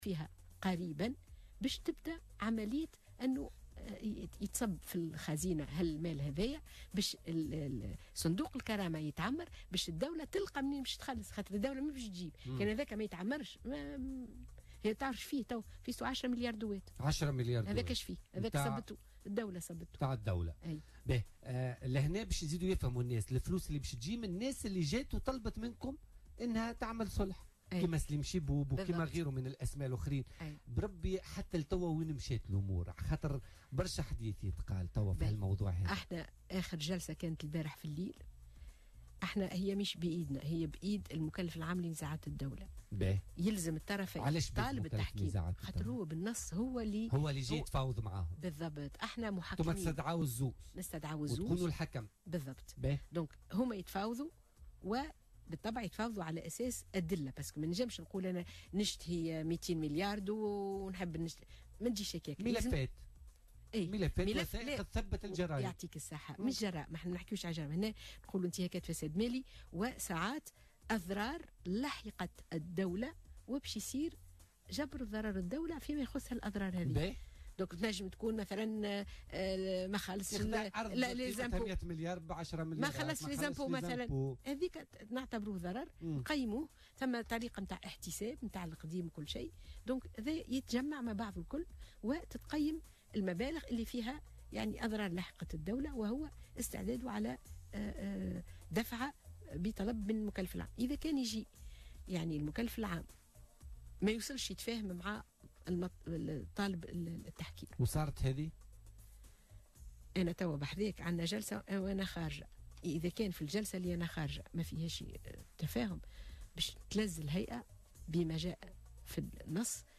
قالت رئيسة هيئة الحقيقة والكرامة والعدالة الانتقالية، سهام بن سدرين ضيفة بوليتكا ، اليوم الاثنين إن ملفات انتهاكات الفساد المالي التي عرضت عليها سيتم البت فيها قريبا.